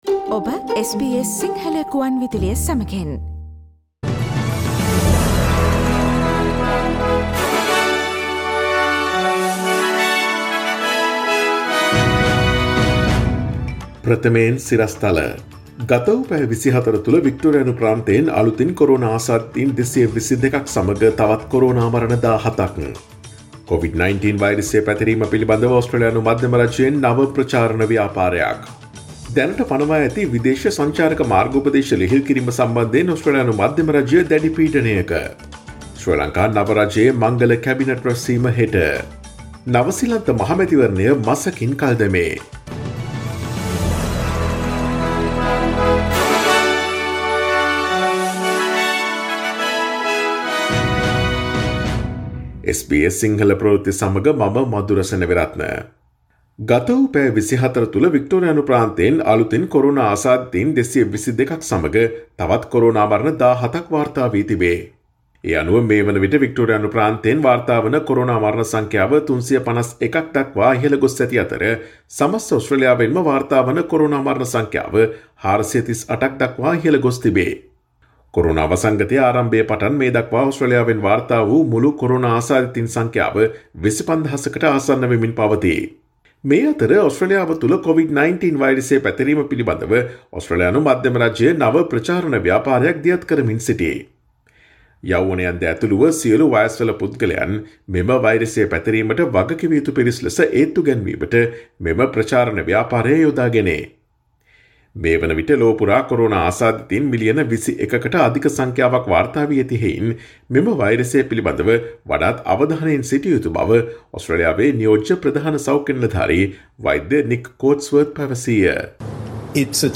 Daily News bulletin of SBS Sinhala Service: Tuesday 18 August 2020